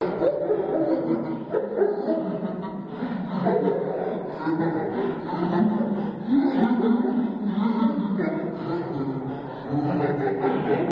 Descarga de Sonidos mp3 Gratis: risa 10.